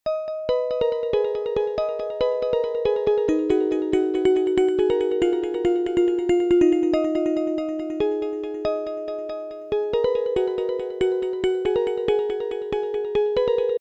• Качество: 320, Stereo
Trance